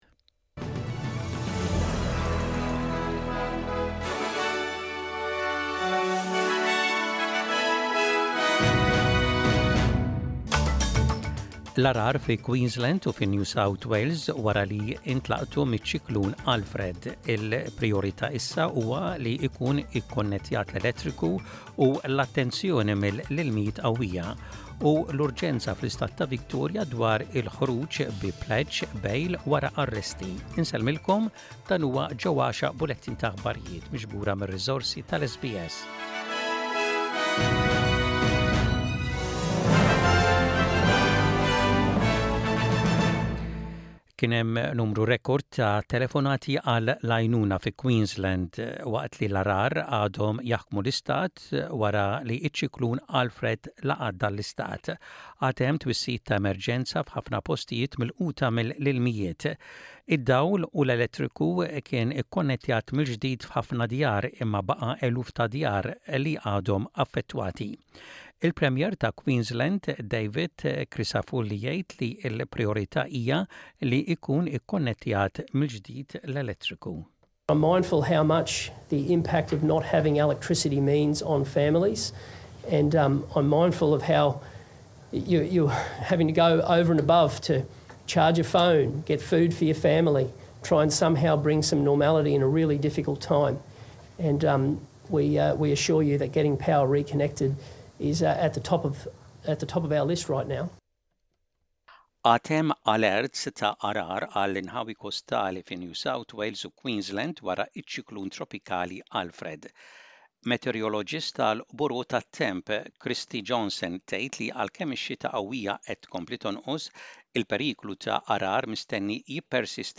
Aħbarijiet bil-Malti: 11.03.25